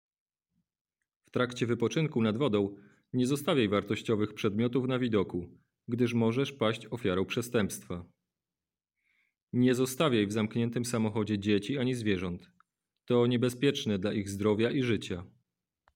Komunikaty, które będą nadawane przez system nagłaśniający w radiowozach, w trakcie wizyt policjantów nad wodą: